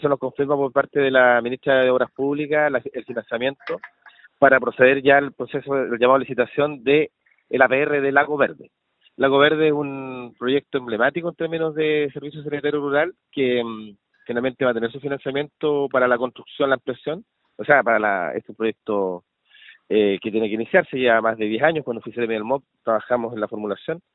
Por su parte, el delegado Presidencial, Jorge Alvial, indicó que se trata de un proyecto emblemático, que se encuentra próximo a ser licitado.
cuna-2-jorge-alvial.mp3